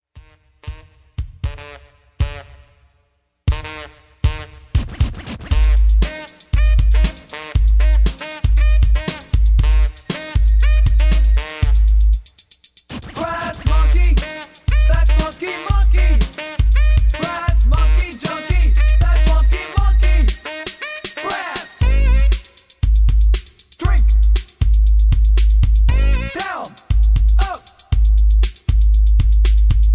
rock
rap